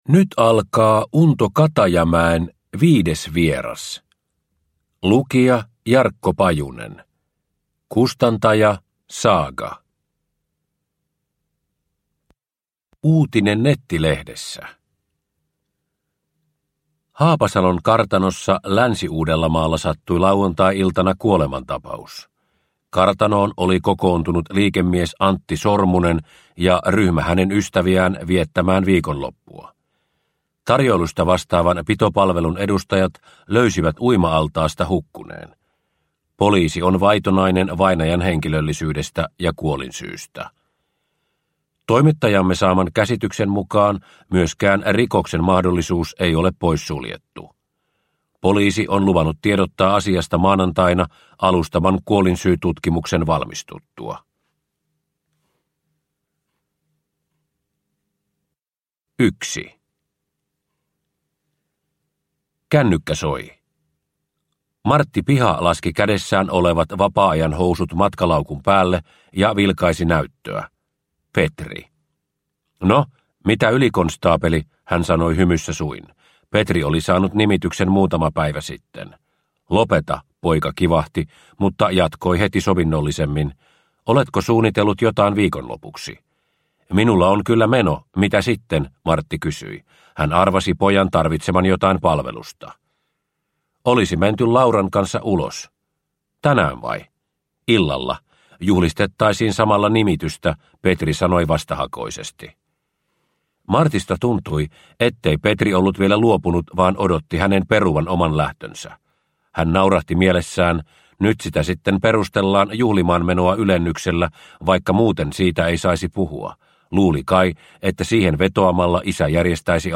Viides vieras / Ljudbok